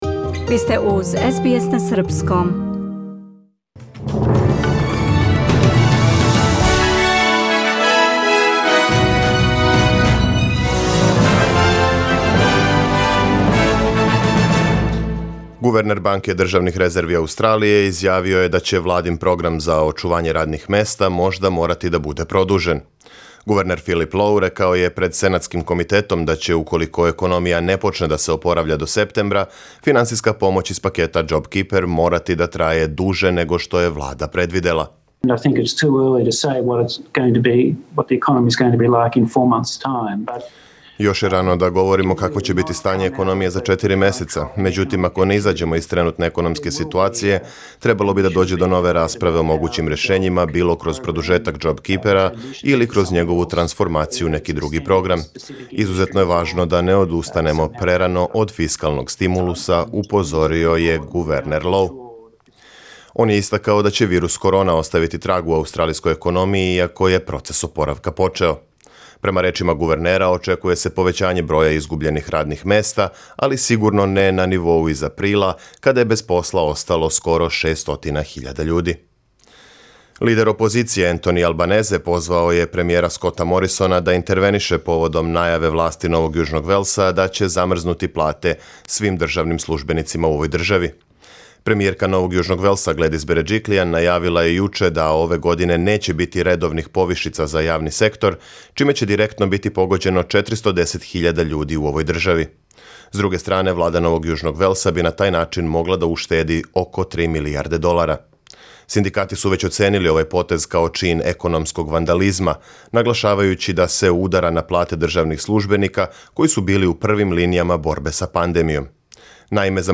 Преглед вести за 28. мај 2020. године